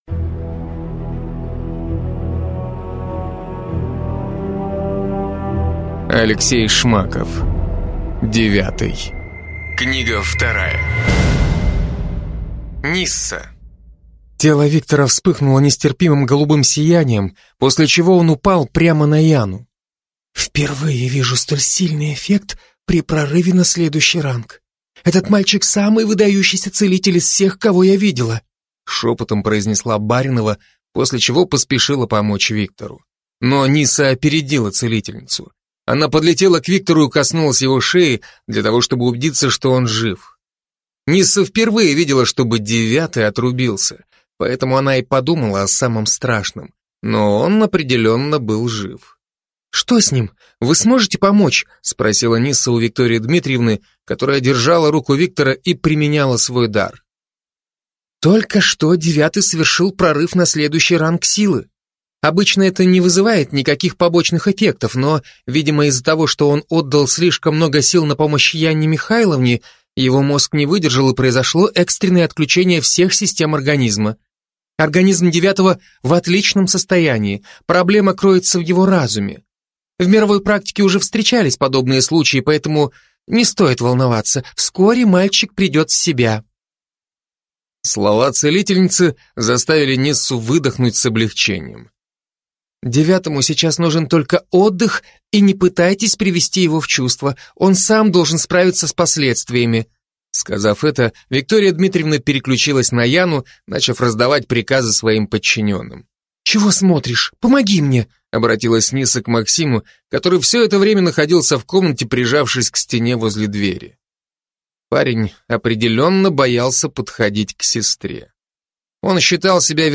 Аудиокнига Девятый 2 | Библиотека аудиокниг